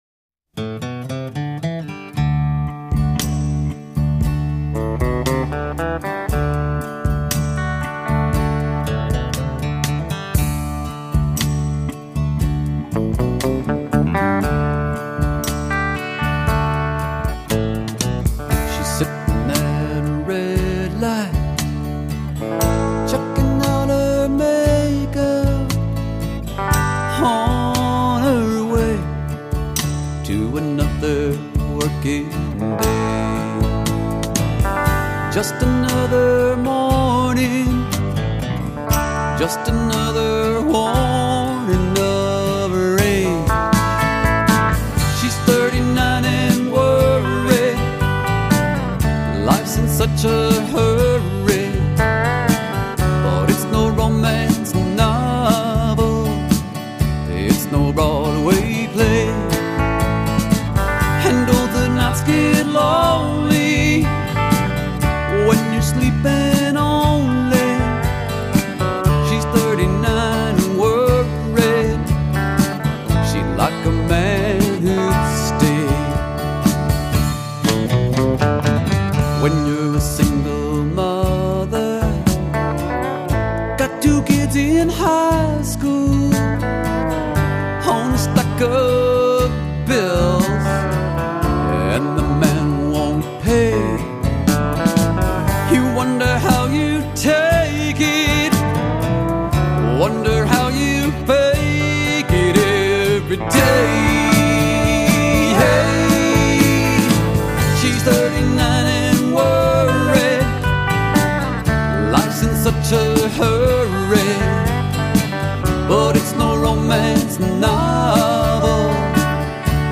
vocals, guitar
Bass
Drums
Telecaster